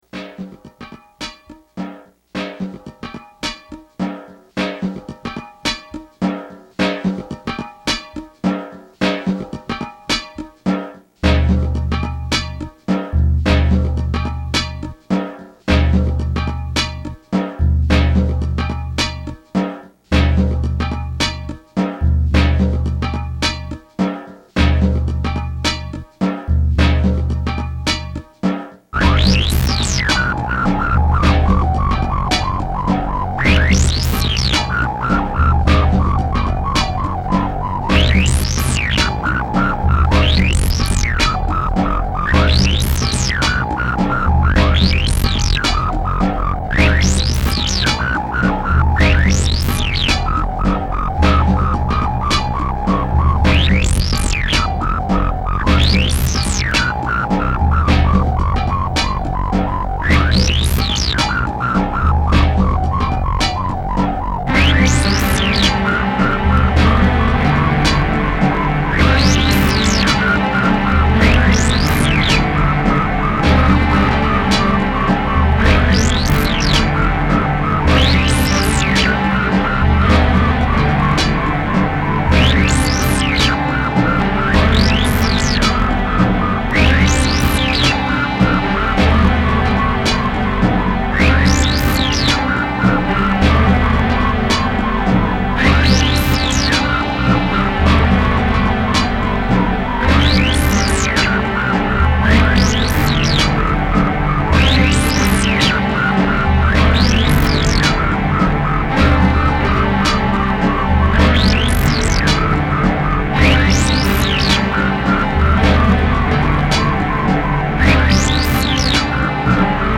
AND I decided to make the songs techno songs.
The samples were captured and run through a Casio FZ-20M sampler (a marvelous, heavy-duty piece of gear from the early 90s), and everything was sequenced using the SQ-80.